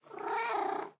cat_purreow2.ogg